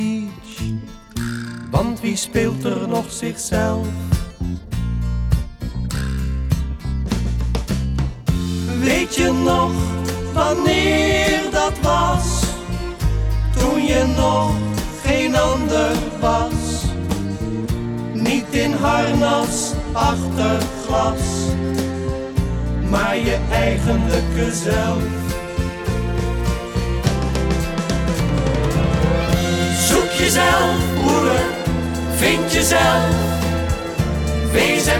# Comedy